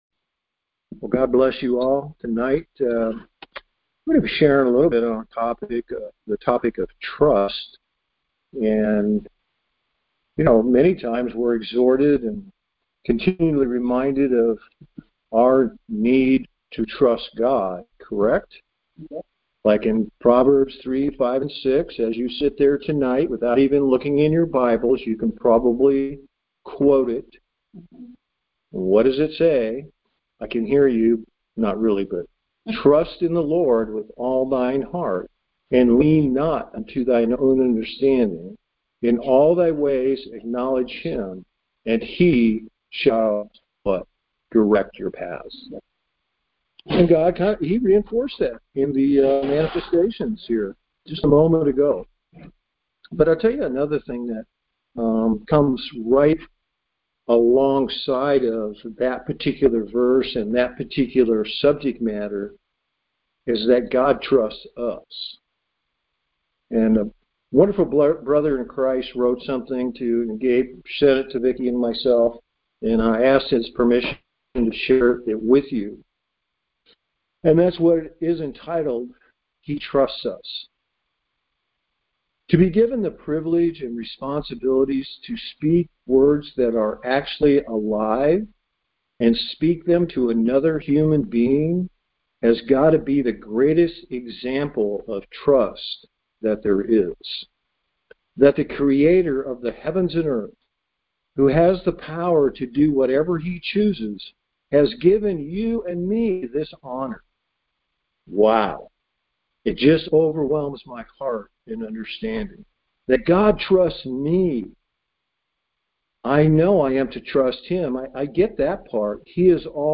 Series: Conference Call Fellowship